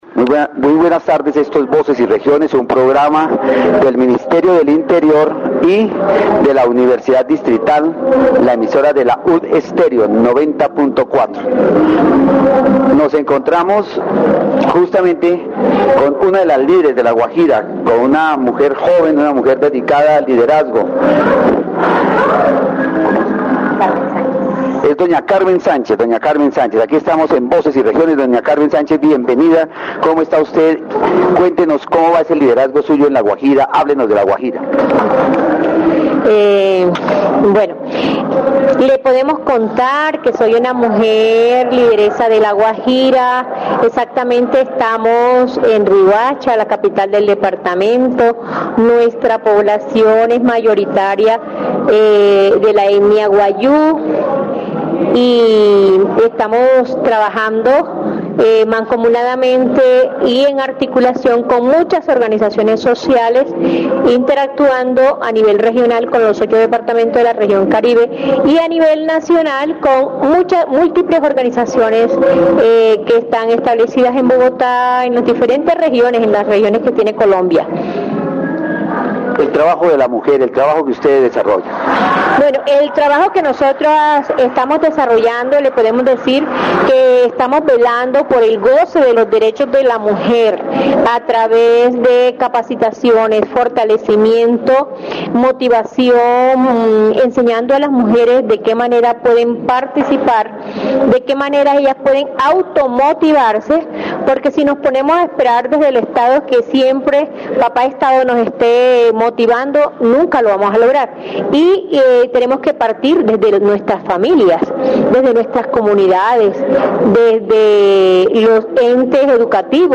In this edition of "Voces y Regiones," several women leaders from different regions of Colombia share their experiences and challenges in their communities.